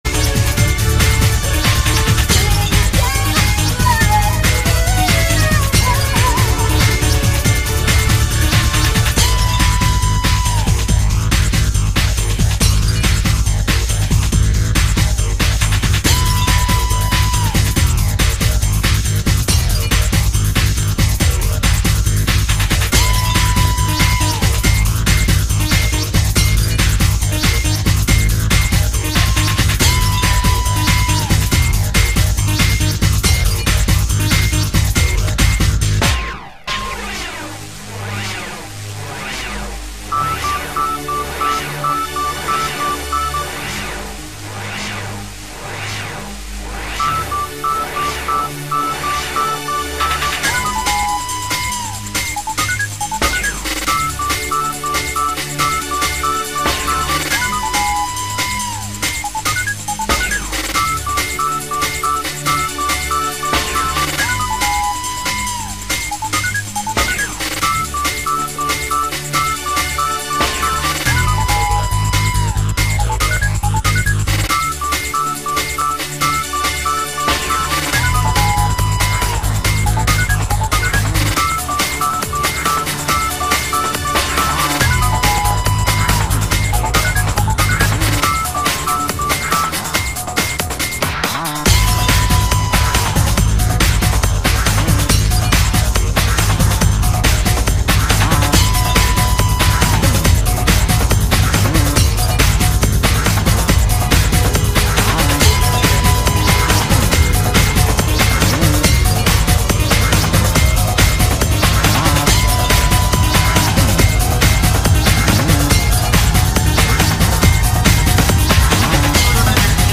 Breaks, Old Skool, Acid House, Warehouse Rave
Oldskool House Rave